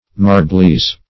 Marbleize \Mar"ble*ize\, v. t. [imp. & p. p. Marbleized; p.